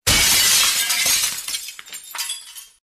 Play, download and share 碎玻璃 original sound button!!!!